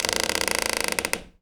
door_creak_med_01.wav